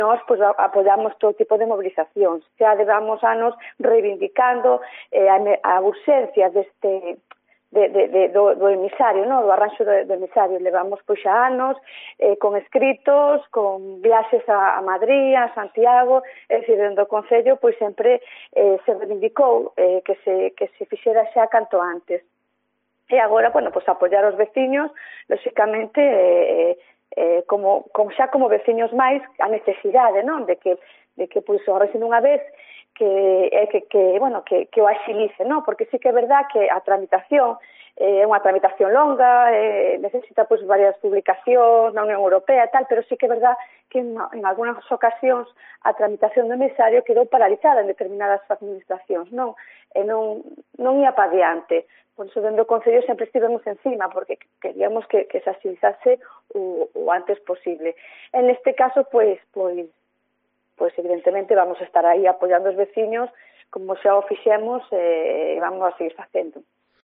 Declaraciones sobre la Playa de Celeiro de la alcaldesa María Loureiro